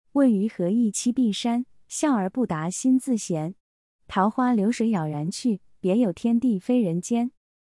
それぞれに中国語の朗読音声も付けておりますの韻を含んだリズム感を聞くことができます。